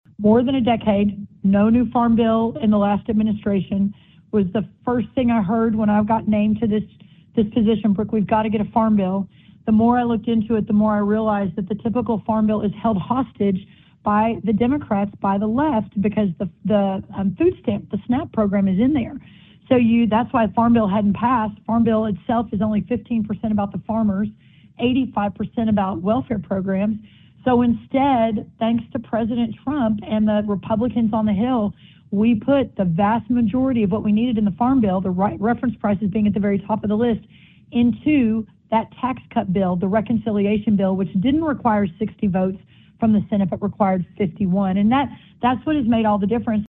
In an interview with the Iowa Agribusiness Radio Network, U.S. Agriculture Secretary Brooke Rollins said those updates were included in the same H.R. 1 legislation passed last summer that also addressed tax policy affecting agriculture.